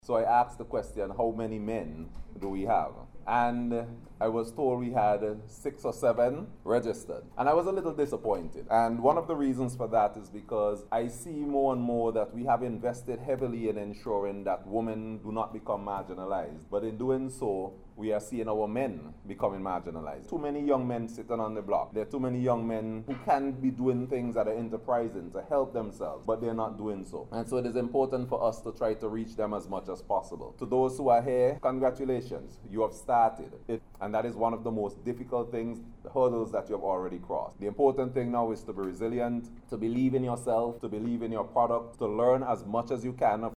Speaking at this morning’s opening ceremony of a business seminar hosted in partnership with the Centre for Enterprise Development